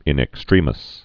(ĭn ĕk-strēmĭs)